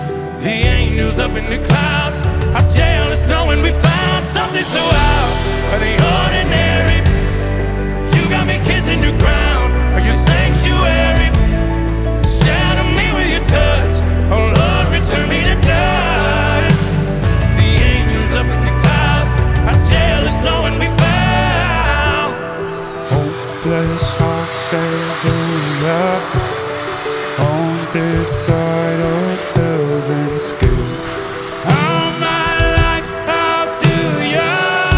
Жанр: Поп музыка
Pop